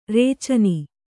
♪ rēcani